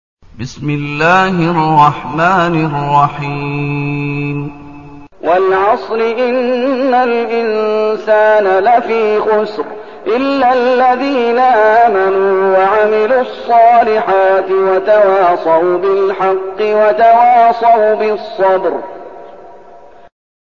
المكان: المسجد النبوي الشيخ: فضيلة الشيخ محمد أيوب فضيلة الشيخ محمد أيوب العصر